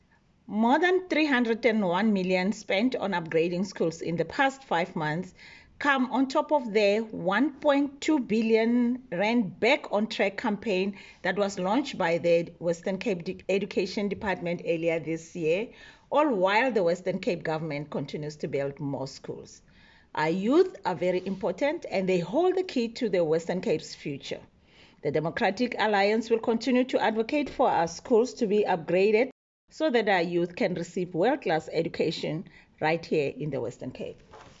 attached an audio clip by MPP Matlhodi Maseko